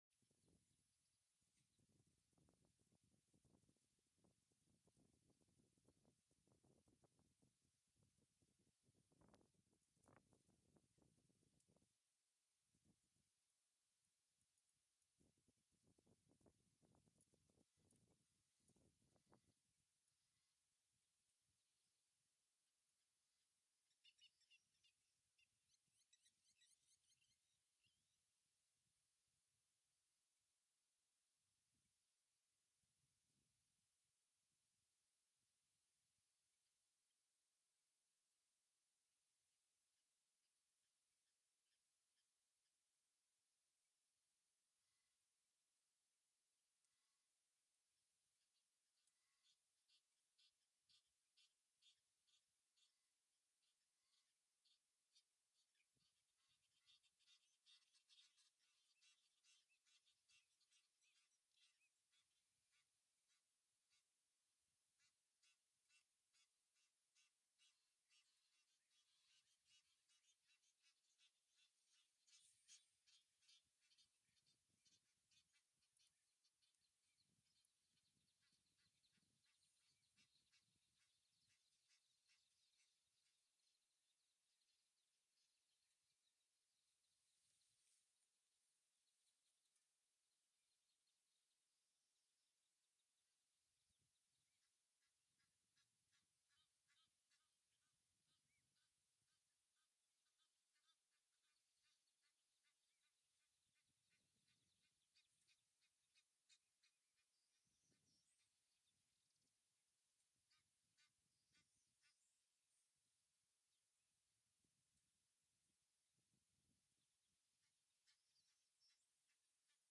OWI " 布什氛围
描述：在清晨记录在游戏小屋。
Tag: 鸟类 宁静 FRE SH 春天 自然 现场记录 OWI